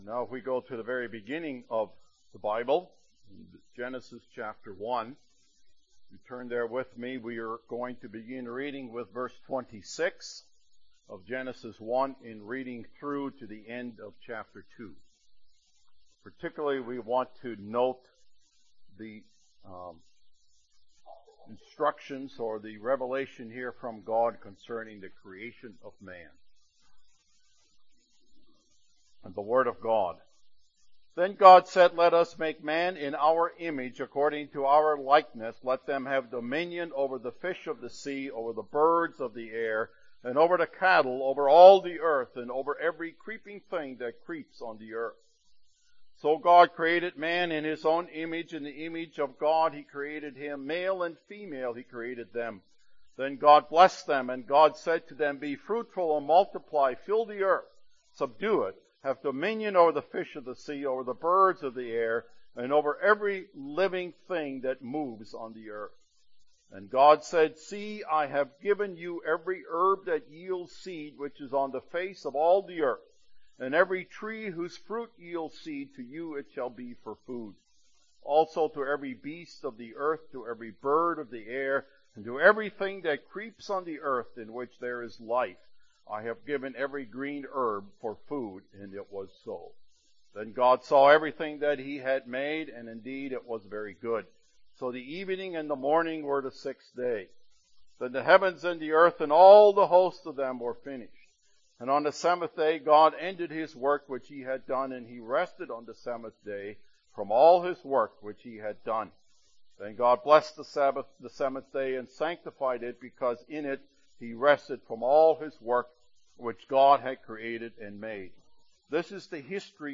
The Most Important Question | SermonAudio Broadcaster is Live View the Live Stream Share this sermon Disabled by adblocker Copy URL Copied!